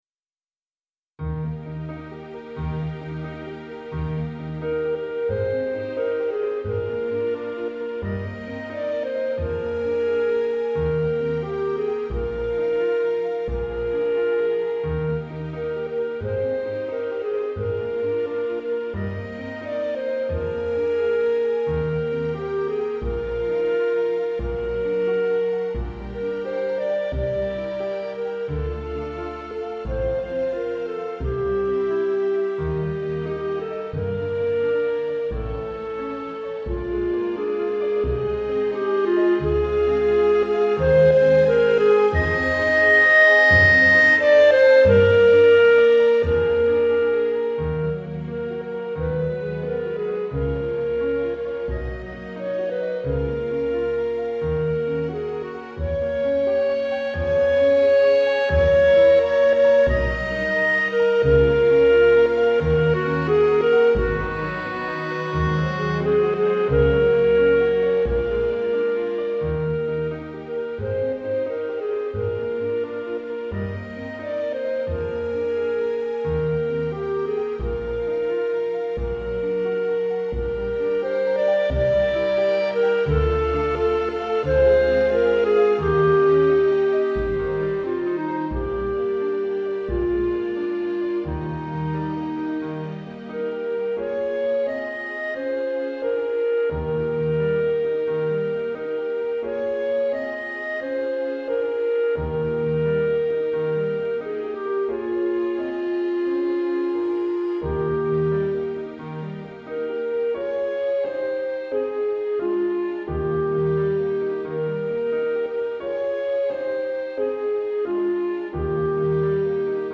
Répertoire pour Musique de chambre